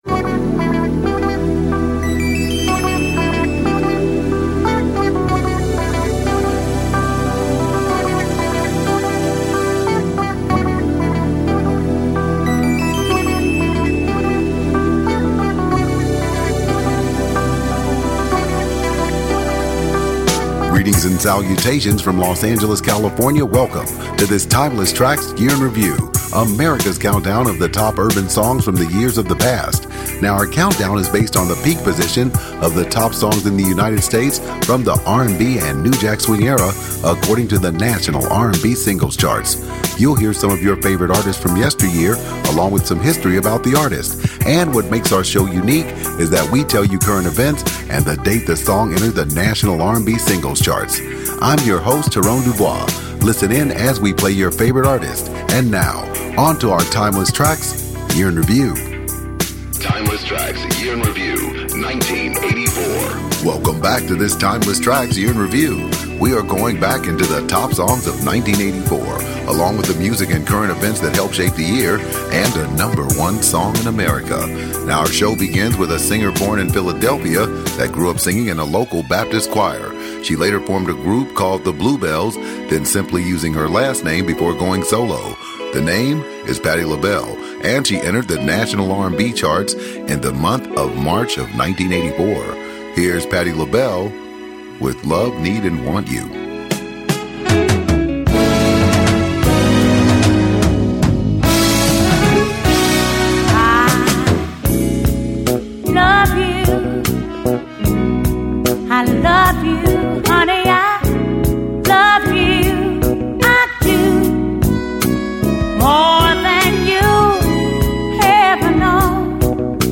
If you like "Old School" Music, you will LOVE Timeless Traxx. It's the countdown of the top R&B songs in America over the last 6 decades according to the chart. This week, we go back to 1984 top jams.